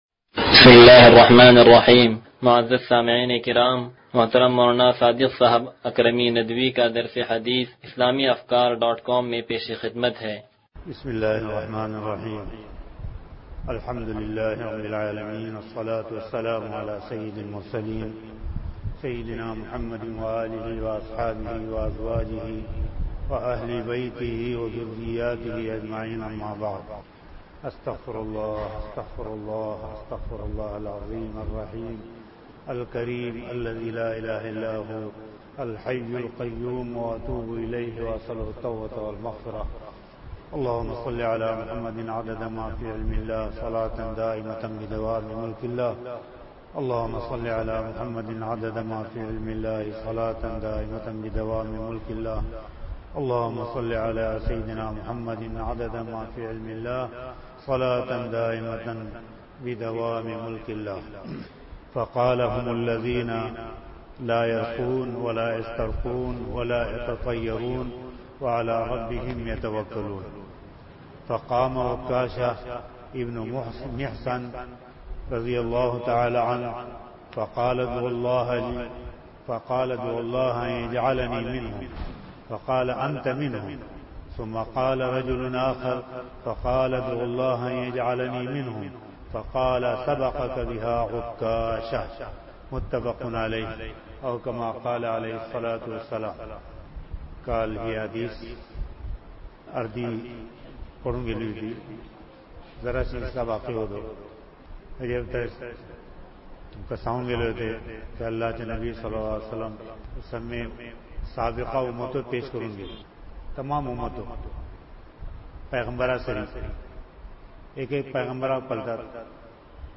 درس حدیث نمبر 0078